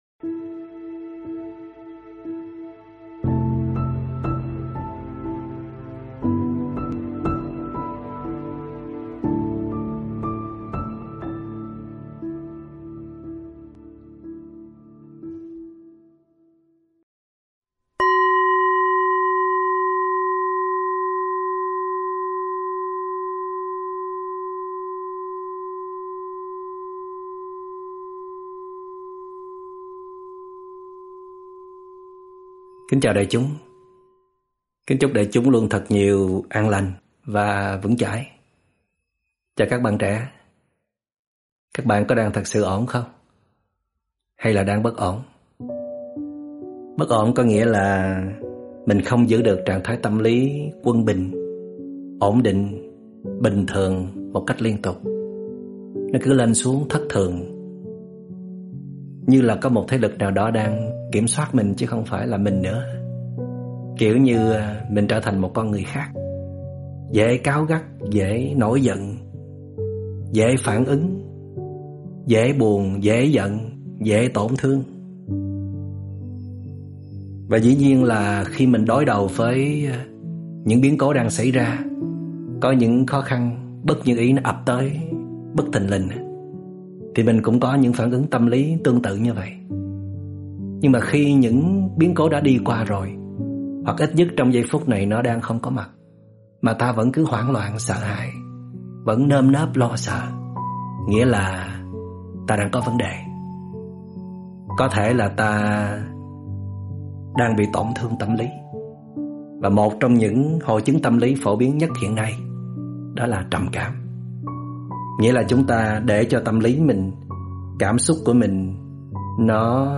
Nghe Pháp âm Thừa nhận mình trầm cảm là bước chữa lành đầu tiên do TS. Thích Minh Niệm giảng